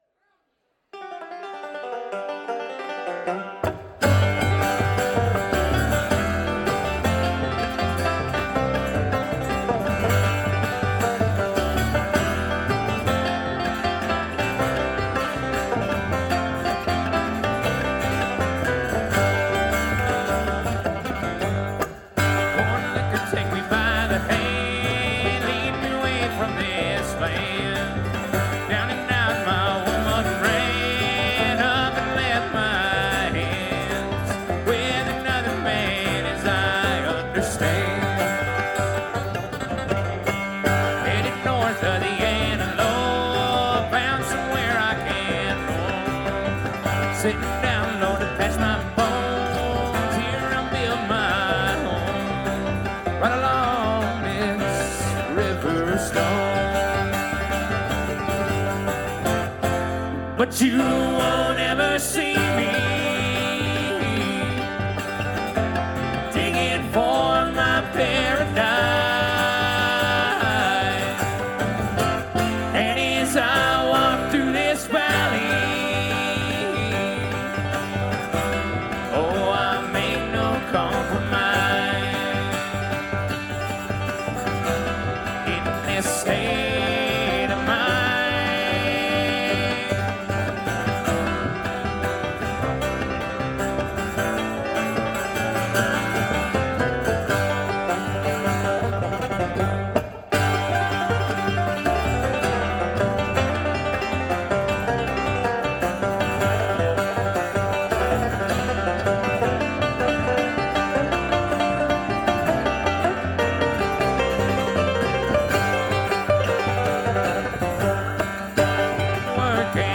Live Harrisburg, PA
A Big Sky Country Bluegrass Band that calls Montana home.
Banjo
Mandolin